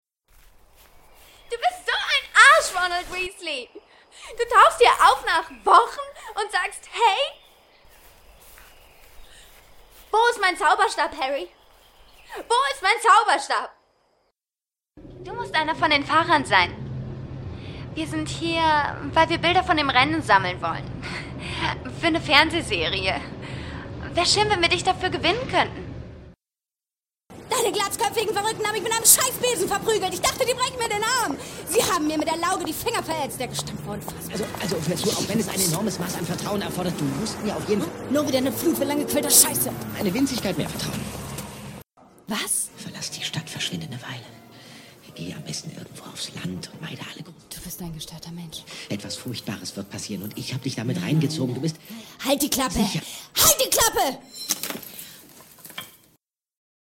Sprechprobe: eLearning (Muttersprache):
Fresh, young, warm, rich in variety, sensual or funny, quiet, gentle and charming voice.